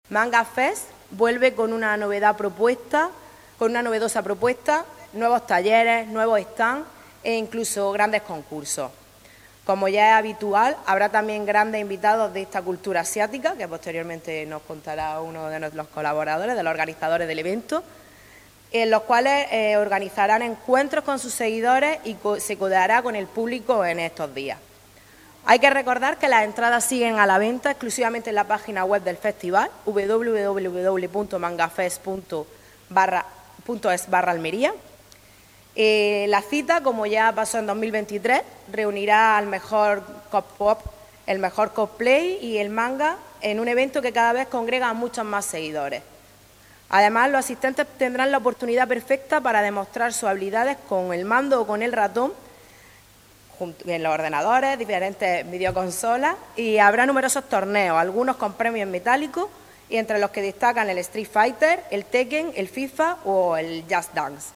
LORENA-NIETO-CONCEJALA-EMPLEO-COMERCIO-JUVENTUD-Y-EMPRENDIMIENTO-MANGAFEST-2025.mp3